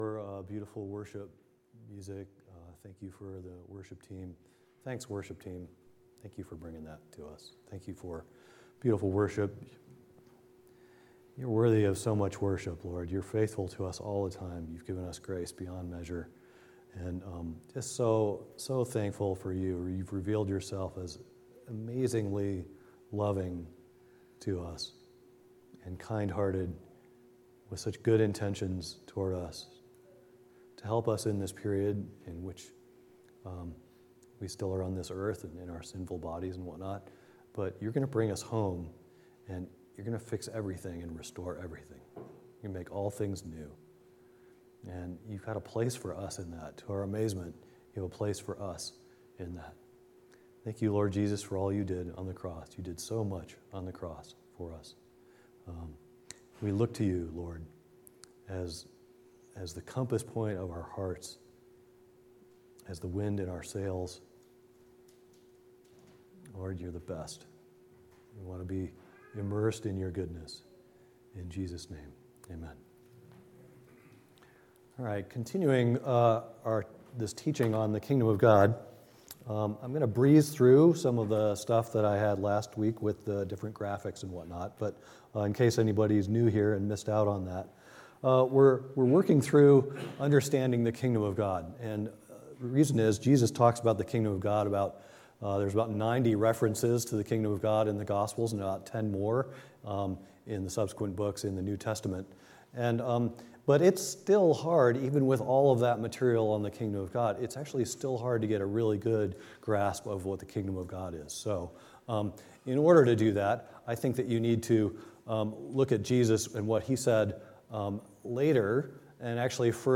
Sunday Service Service Type: Sunday Morning « What is the Kingdom of God Part 1 What is the Kingdom of God Part 2